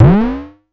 Vermona Perc 03.wav